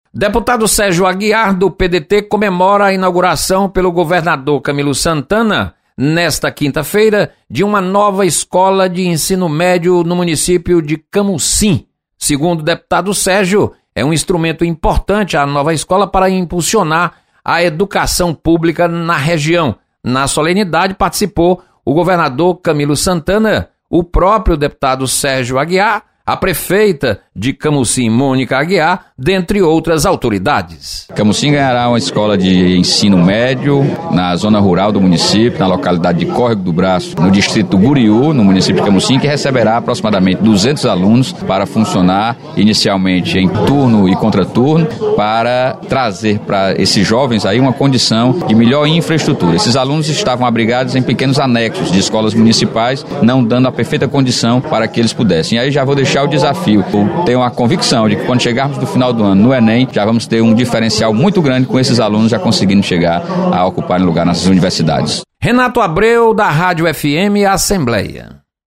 Deputado Sérgio Aguiar ressalta inauguração de escola de Ensino Médio. Repórter